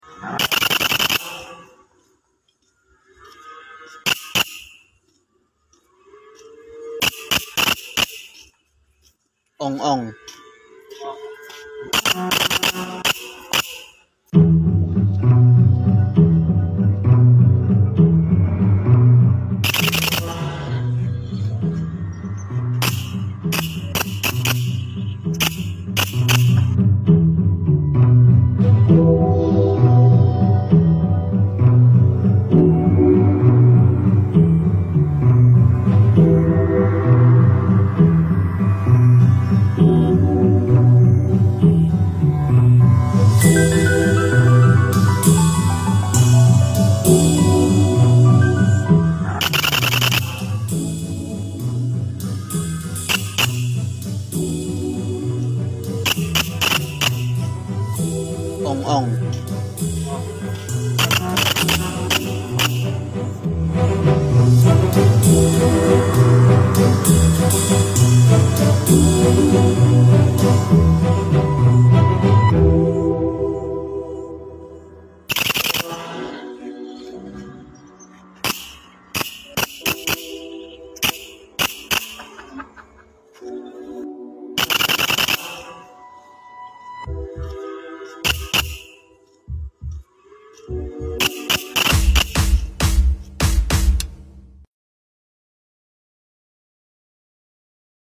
King of rogidan or giant sound effects free download
He make sound likes helicopter and scared us in the middle of nowhere...